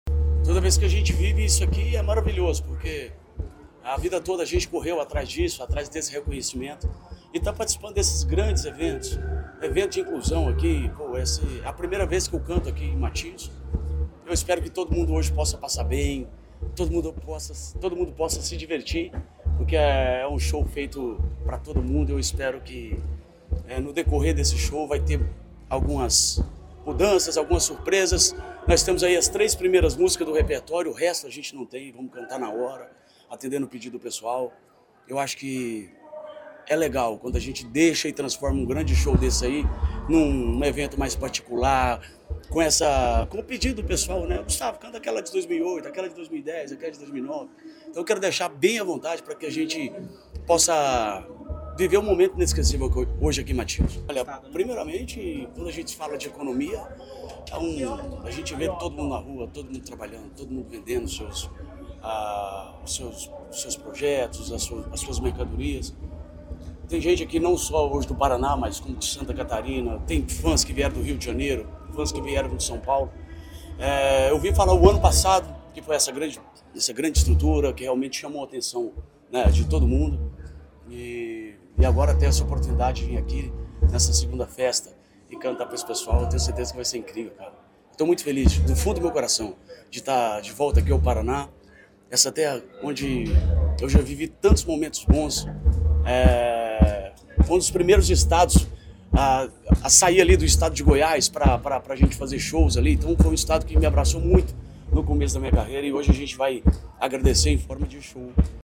Sonora do cantor Gusttavo Lima sobre o show neste domingo, em Matinhos, pelo Verão Maior Paraná